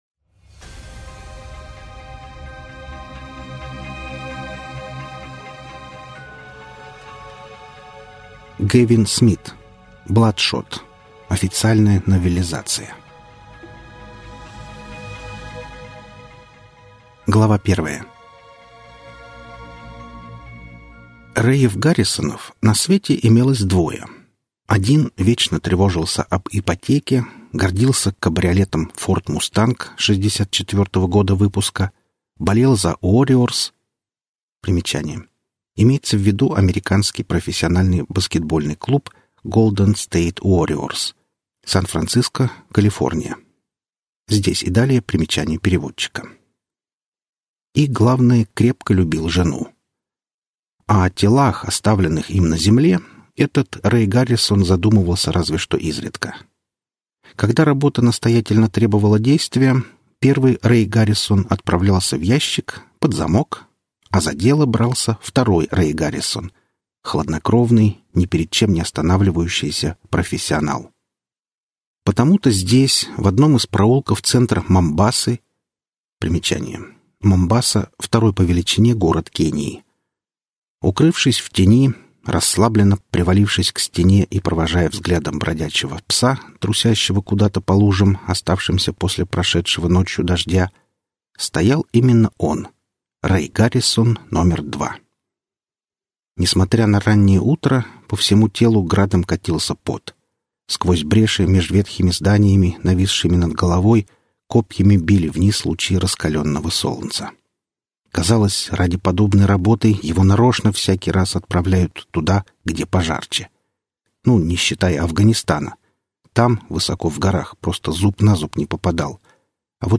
Аудиокнига Бладшот | Библиотека аудиокниг